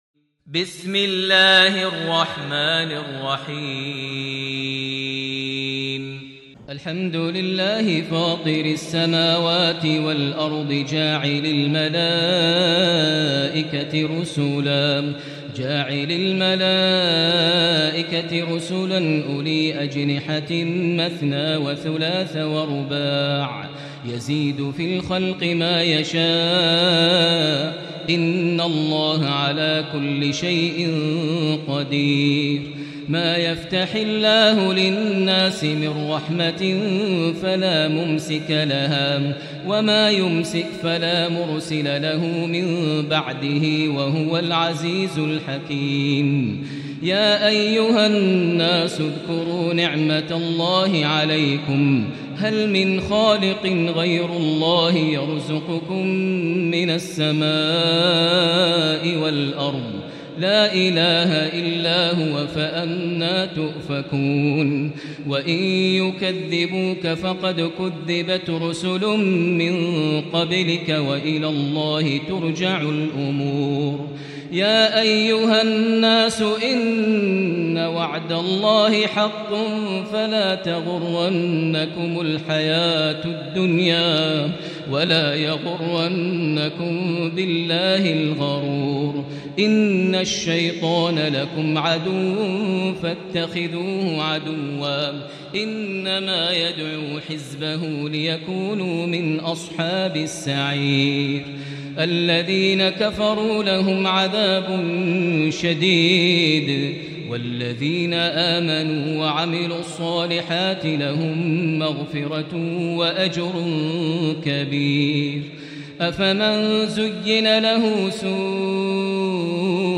سورة فاطر > مصحف الشيخ ماهر المعيقلي (2) > المصحف - تلاوات ماهر المعيقلي